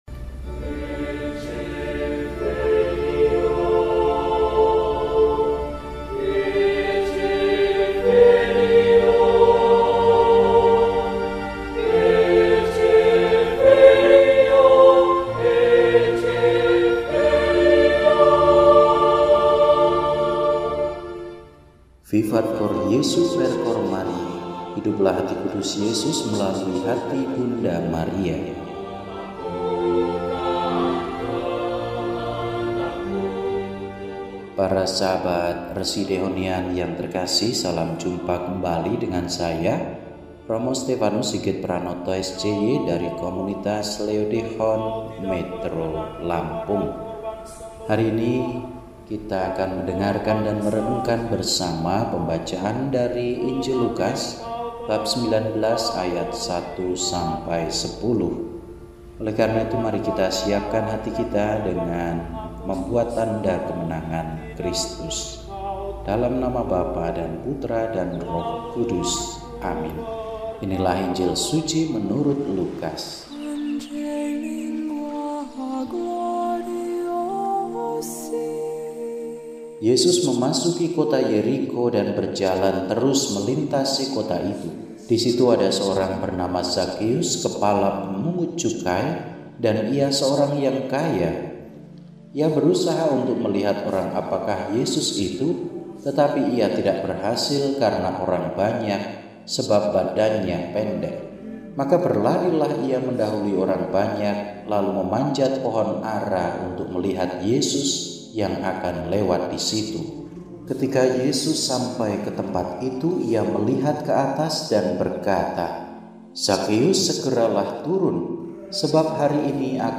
Selasa, 19 November 2024 – Hari Biasa Pekan XXXIII – RESI (Renungan Singkat) DEHONIAN